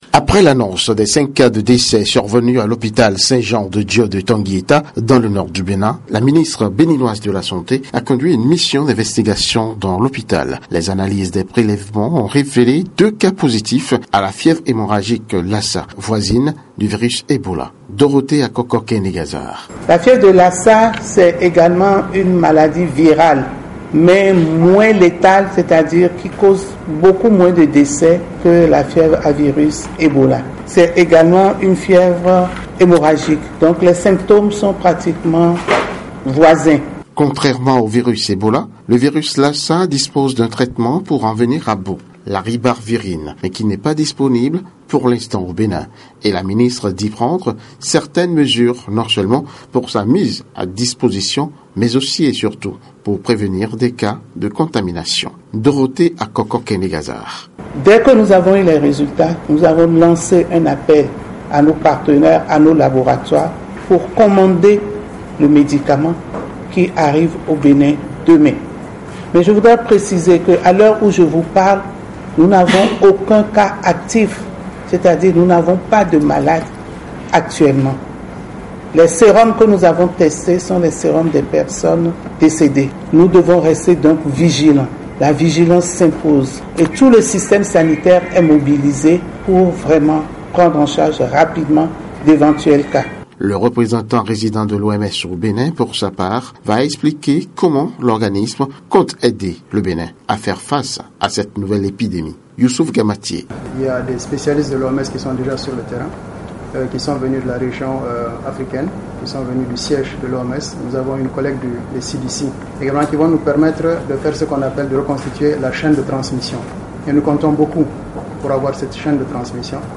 Ecoutez notre correspondant